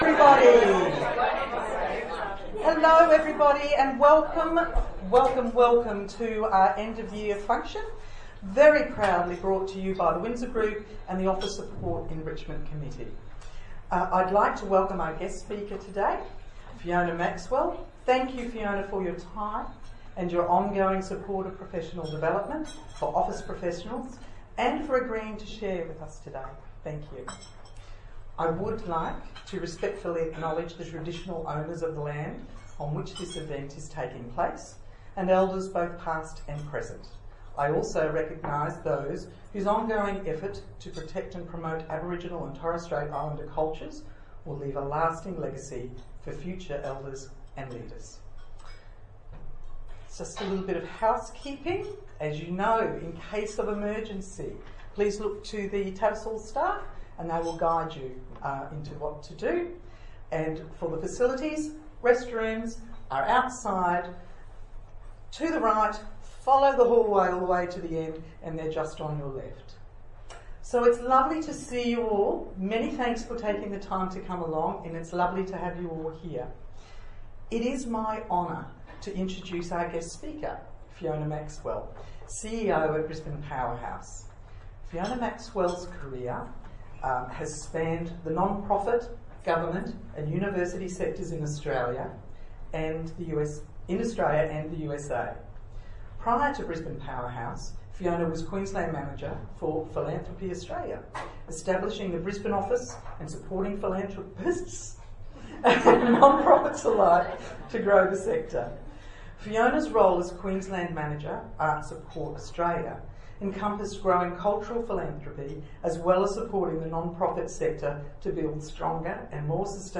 A special celebratory lunch held in December 2017.